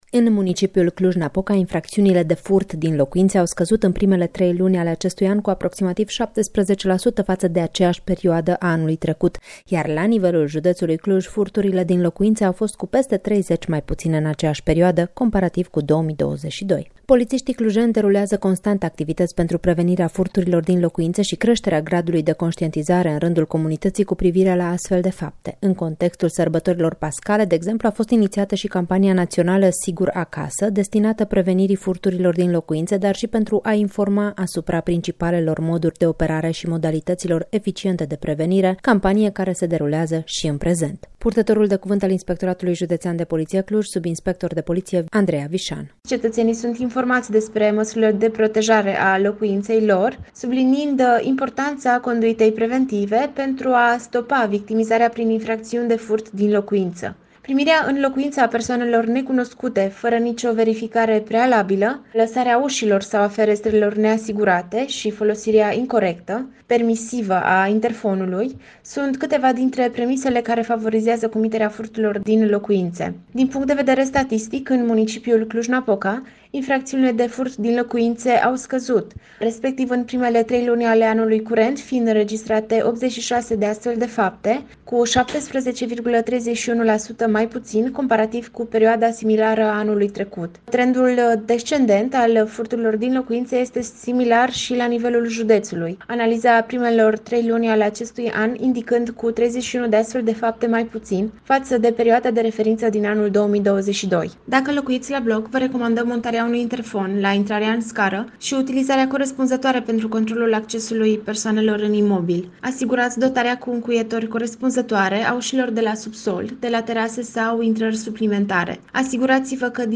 reportaj-sisteme-de-siguranta.mp3